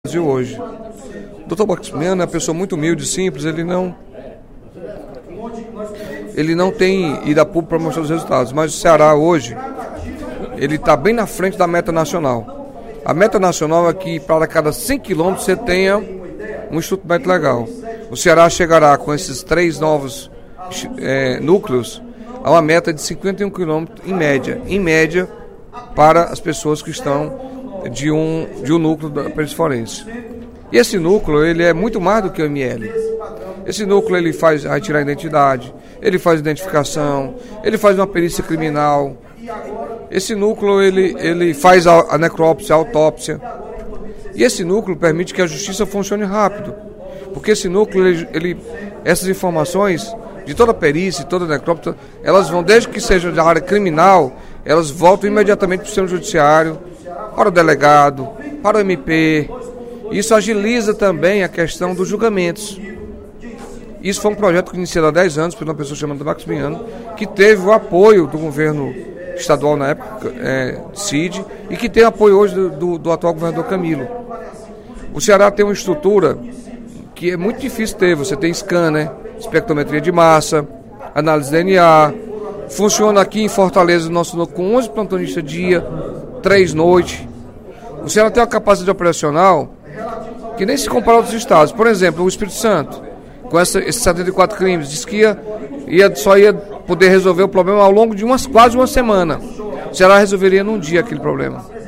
O deputado Carlos Felipe (PCdoB) destacou, durante o primeiro expediente da sessão plenária desta quarta-feira (15/02), o trabalho desenvolvido pela Perícia Forense do Estado do Ceará (Pefoce) ao longo de nove anos de existência.
Em aparte, o líder do Governo na Casa, deputado Evandro Leitão (PDT), salientou a importância de valorizar um órgão que não tem o seu trabalho reconhecido como deveria.